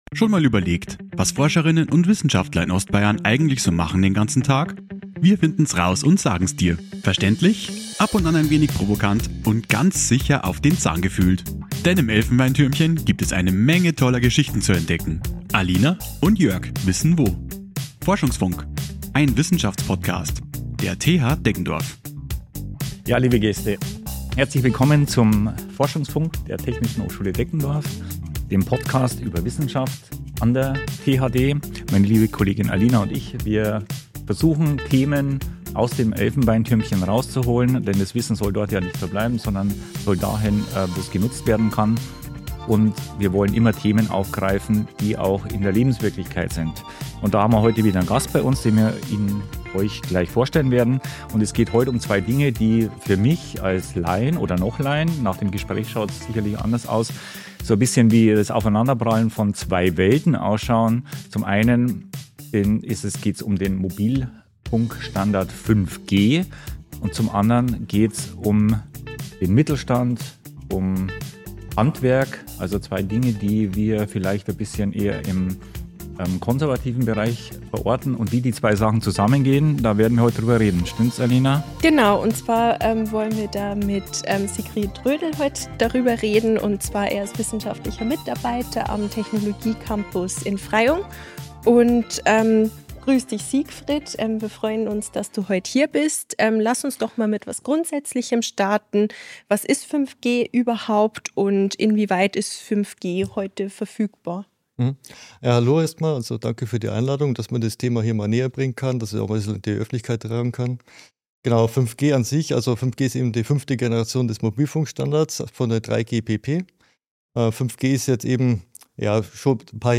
ForschungsFunk #3: Digitalisierung im Handwerk: Wie 5G die Zukunft gestaltet ~ Der Campustalk der THD Podcast